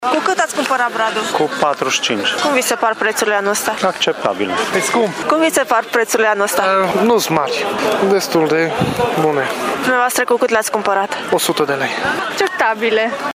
Cumpărătorii se plâng de preţurile sunt mari și speră ca în apropierea Crăciunului brazii să se mai ieftinească.